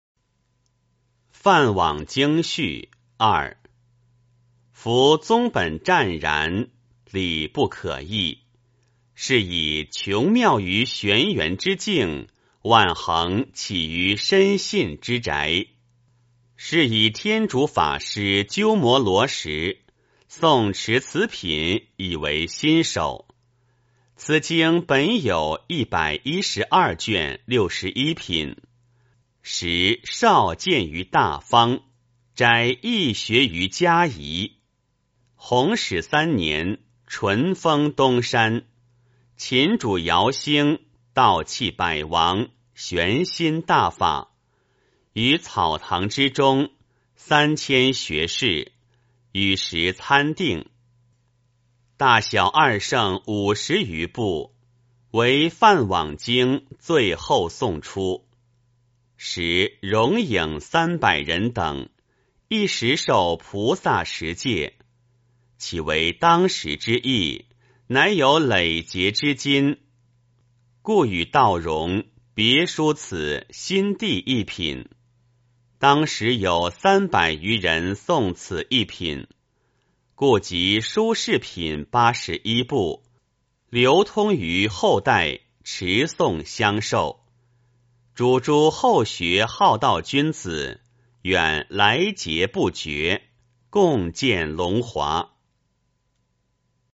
梵网经序二 诵经 梵网经序二--未知 点我： 标签: 佛音 诵经 佛教音乐 返回列表 上一篇： 佛顶尊胜陀罗尼经-3 下一篇： 梵网经-十金刚心 相关文章 大悲咒（藏音）--昌列寺 大悲咒（藏音）--昌列寺...